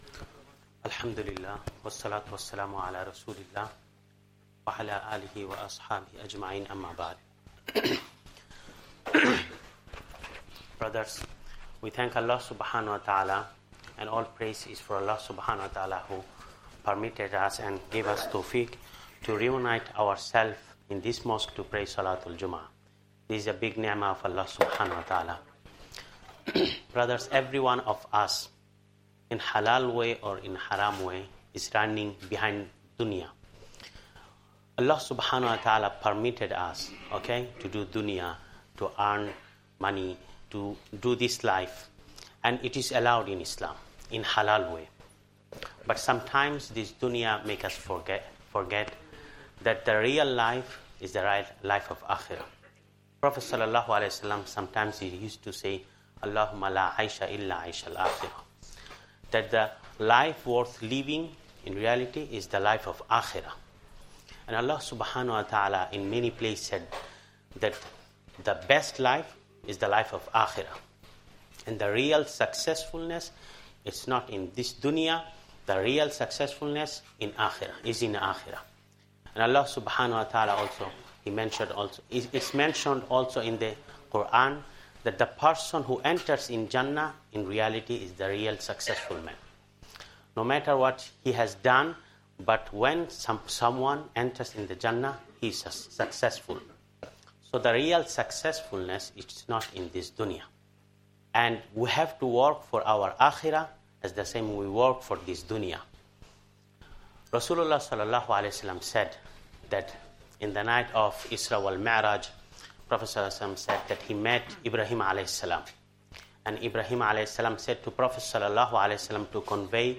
Jummah Talk and 2nd Khutbah